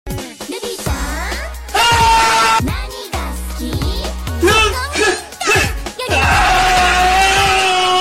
ye swu chiyan Meme Sound Effect